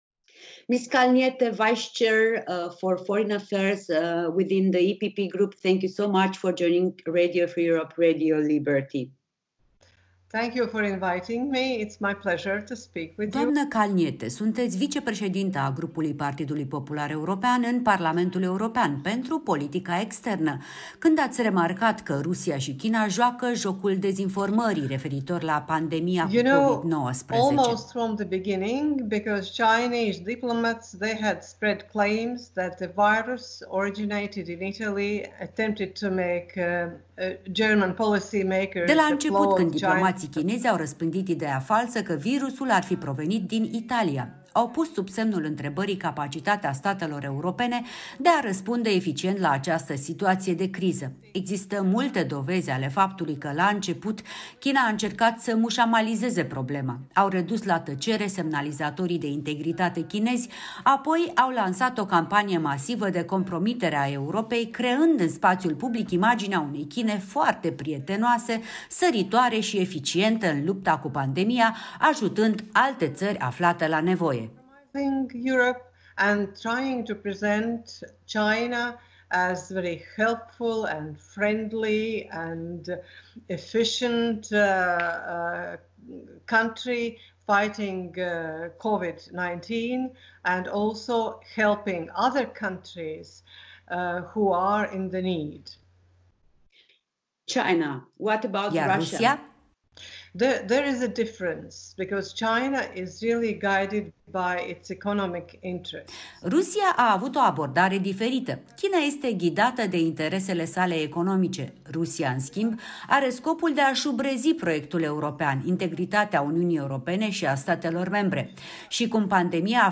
Interviu cu Sandra Kalniete